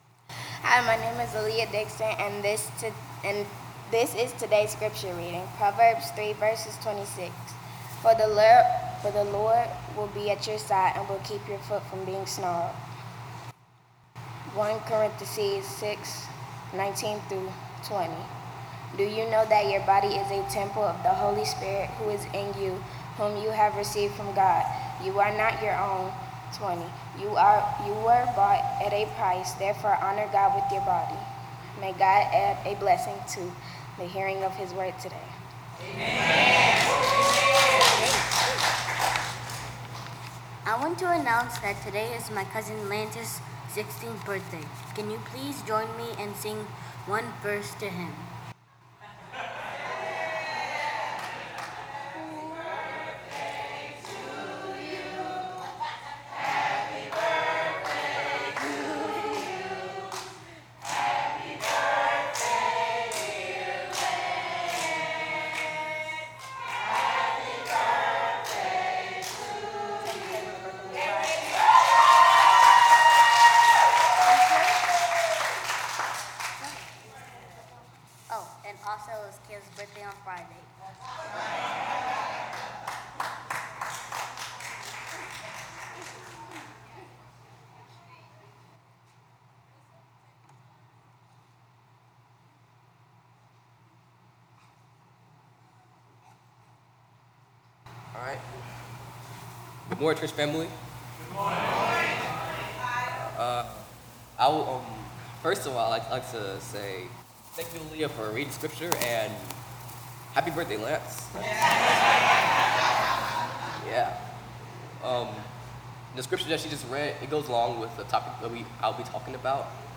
Worship Service 9/24/17